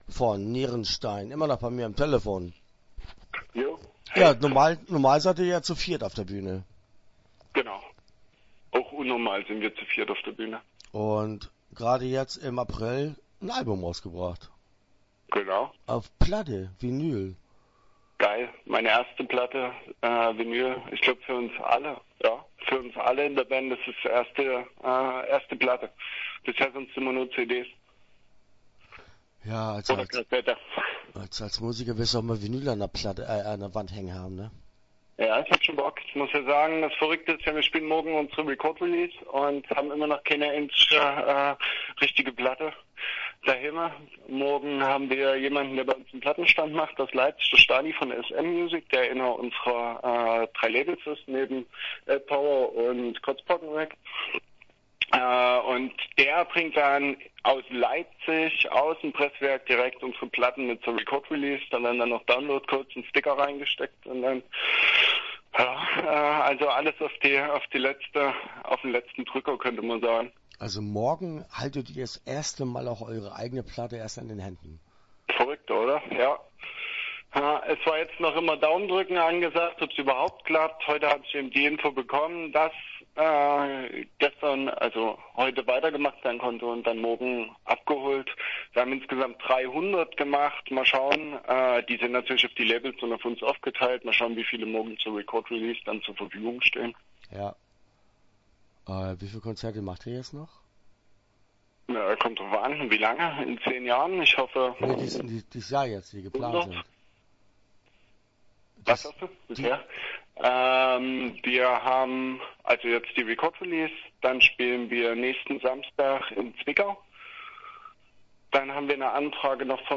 Start » Interviews » Nierenstein